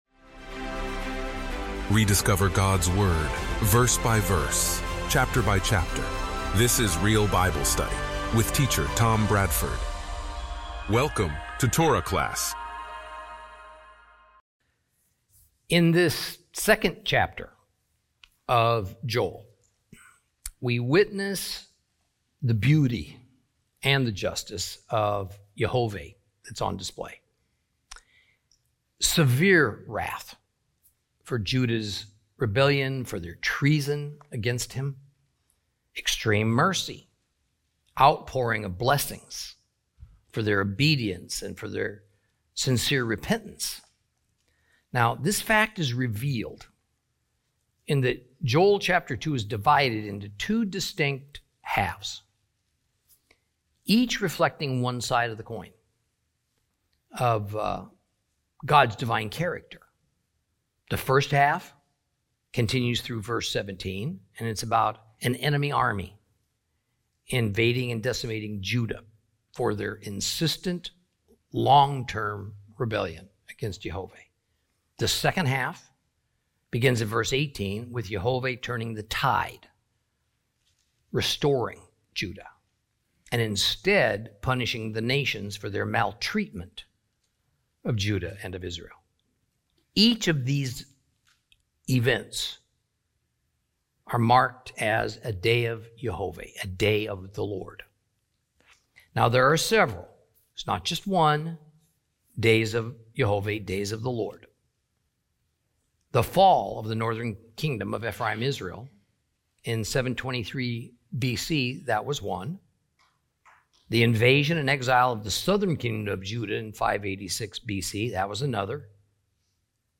Teaching from the book of Joel, Lesson 5 Chapter 2 continued.